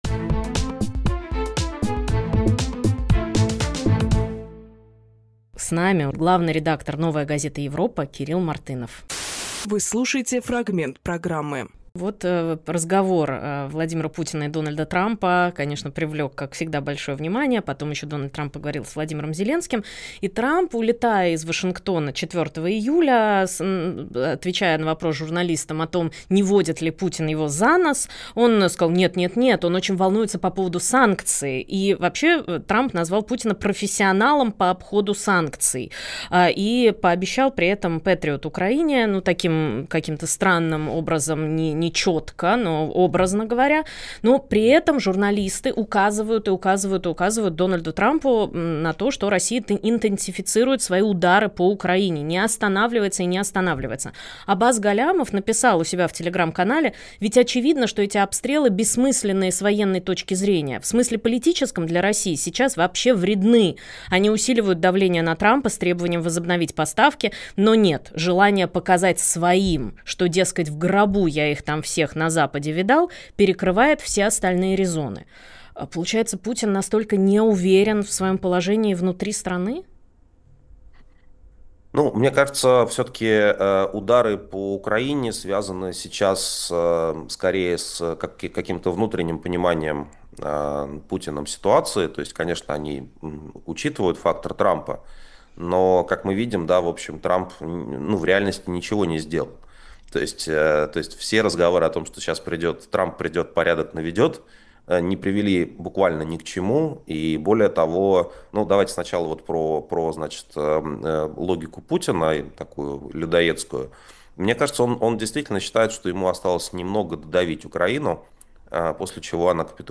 Фрагмент эфира от 06.07.25